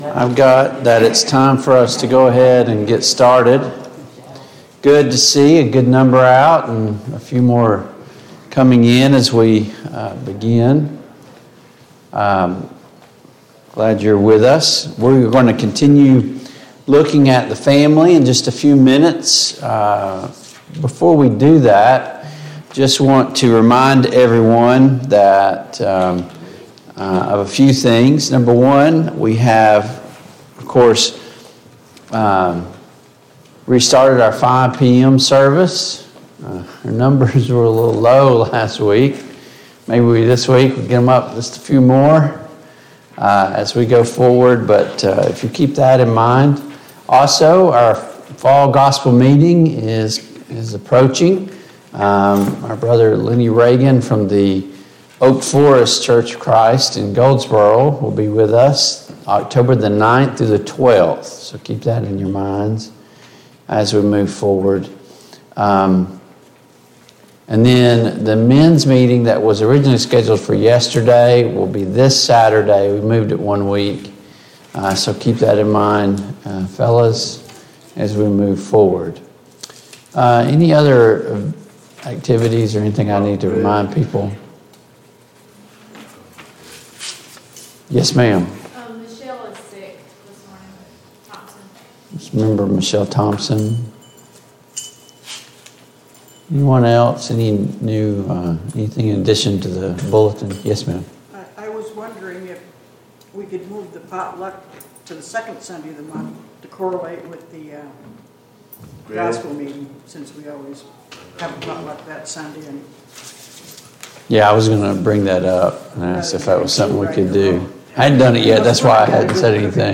Service Type: Sunday Morning Bible Class Download Files Notes Topics: Sin , Temptation , The Family Structure « 46.